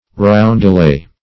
Roundelay \Round"e*lay\, n. [OF. rondelet, dim. of rondel.